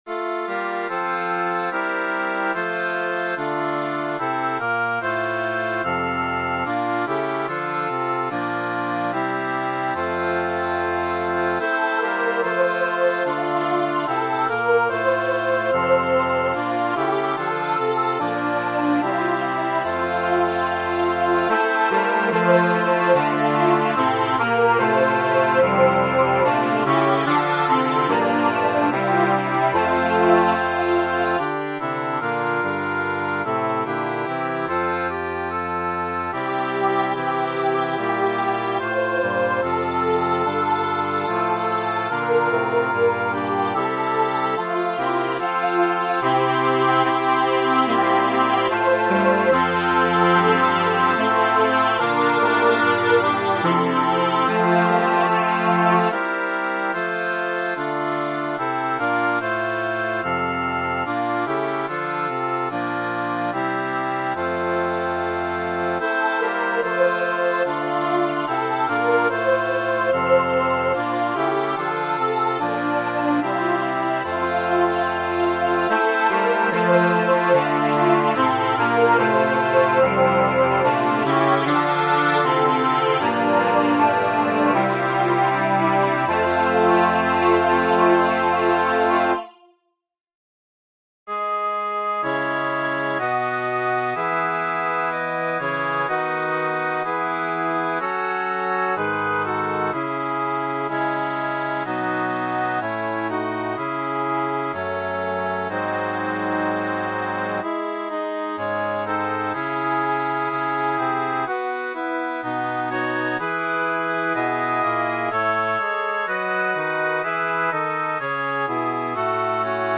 Tonalité : fa majeur